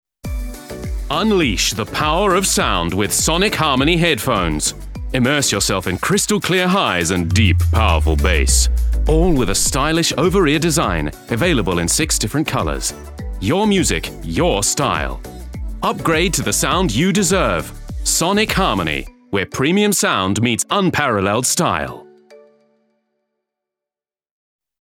Commercial: Upbeat